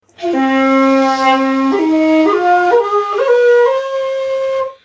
SONS ET LOOPS DE SHAKUHACHIS GRATUITS
Shakuhachi 45